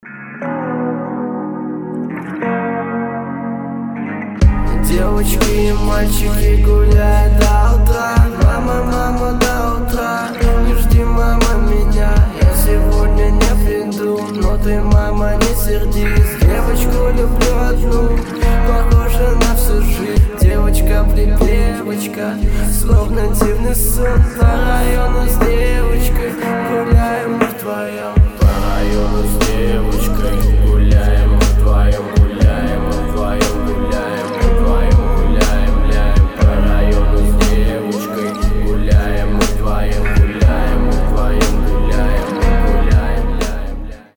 • Качество: 320, Stereo
русский рэп
спокойные
классный бит